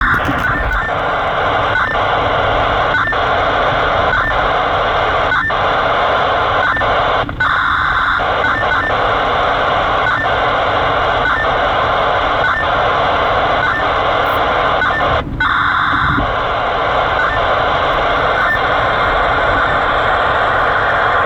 Mode NFM
Modulation FFSK
Bit rate: 1200 bits per second